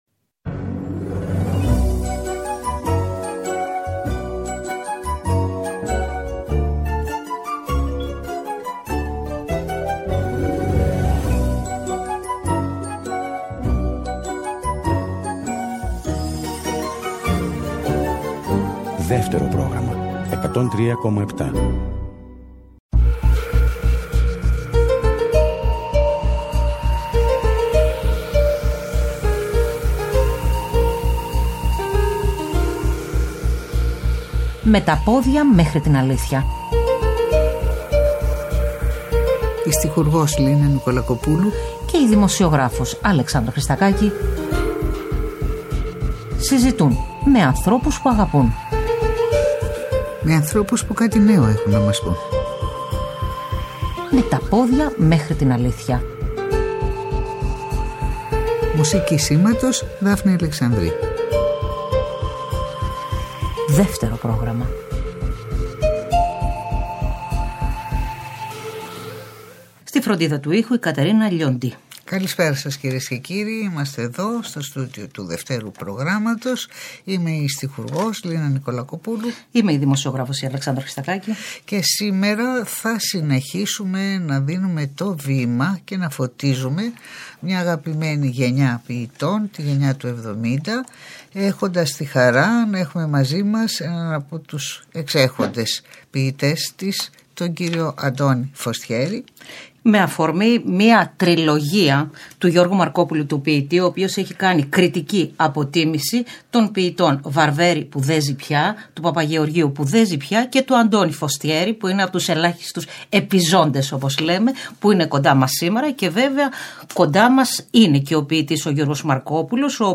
Οι ποιητές Γιώργος Μαρκόπουλος και Αντώνης Φωστιέρης καλεσμένοι στην εκπομπή “ Με τα πόδια μέχρι την αλήθεια” στις 12 Νοεμβρίου 2023 .